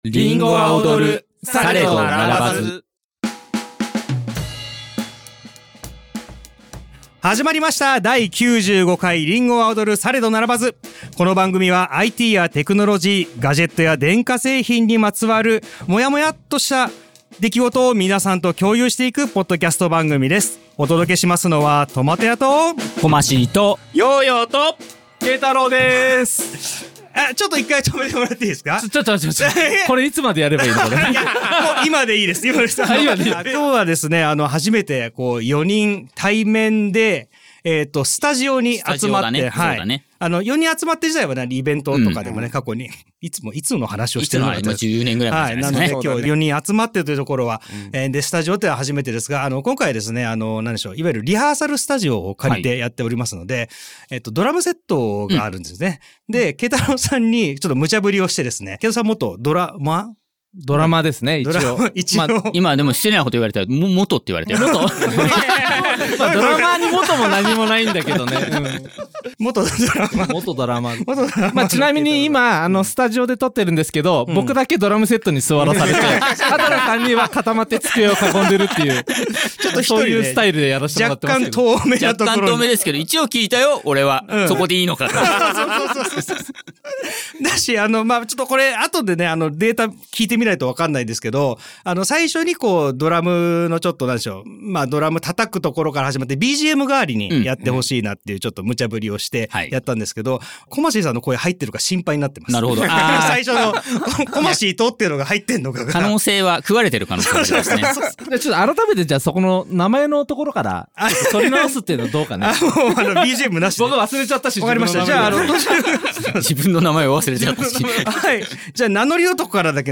4人全員がスタジオに集まっての収録が実現しました。番組冒頭、鳴り響くドラム！メンバーそれぞれが初めて手にしたApple製品から現在愛用しているものまでを改めて語ります。